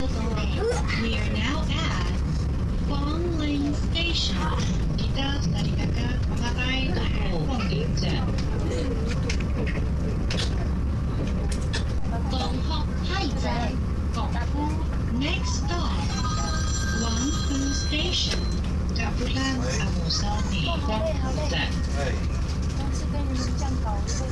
Broadcasting on a train in Eastern Taiwan